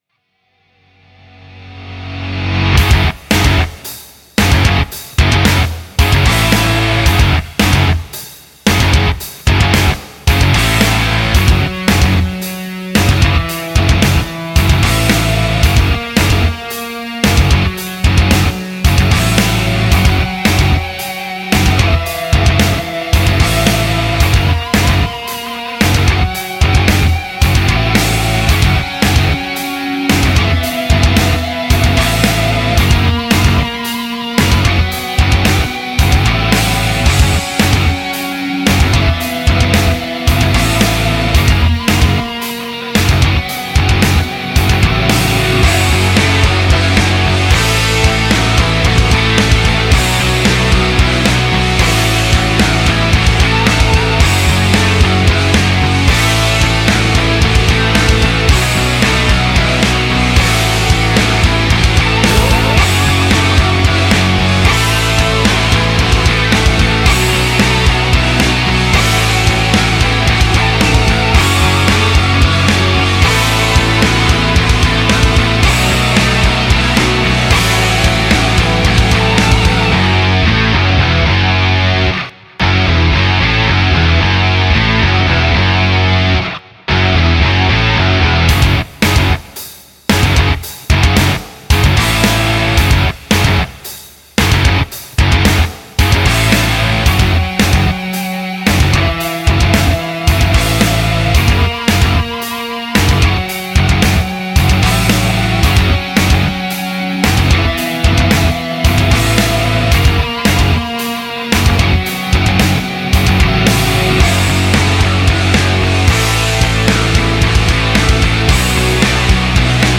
это энергичная композиция в жанре EDM